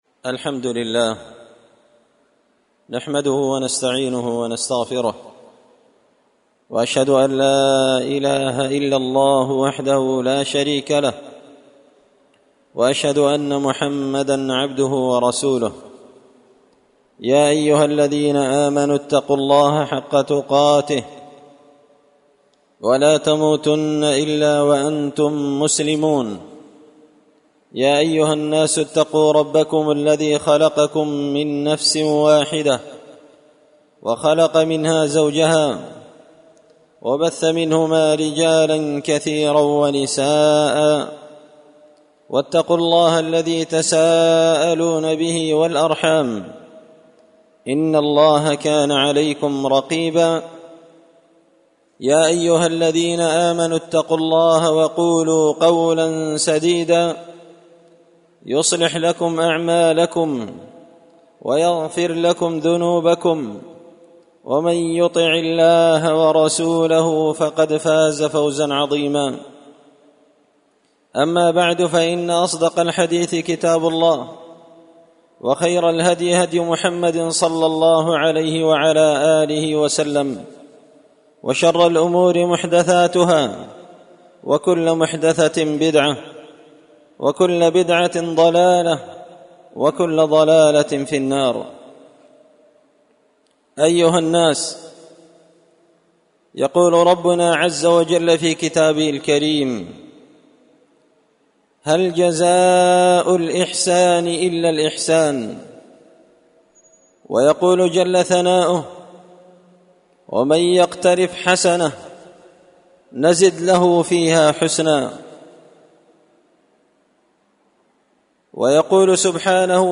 خطبة جمعة بعنوان – الجزاء من جنس العمل
دار الحديث بمسجد الفرقان ـ قشن ـ المهرة ـ اليمن